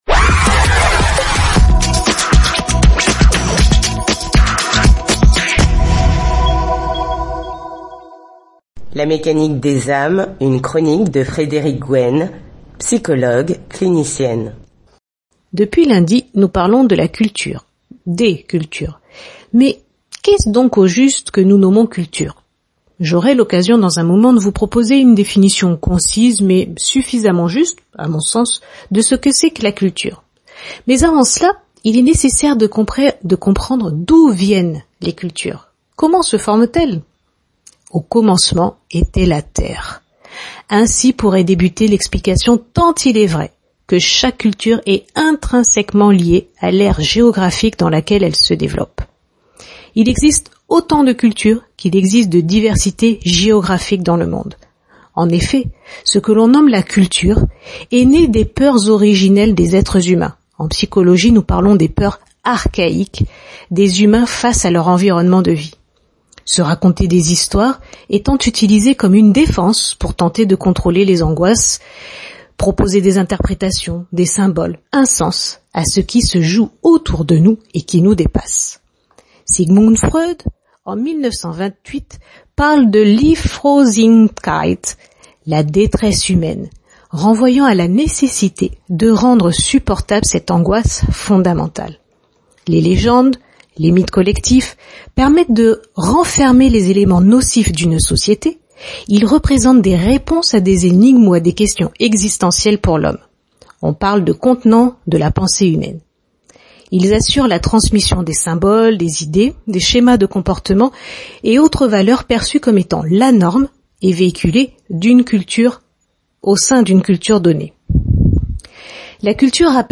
Une chronique à retrouver sur Radio Mayouri Campus La radio du savoir, du lundi au vendredi à 6h40, 7h40, 12h40, 18h40 et 20h40.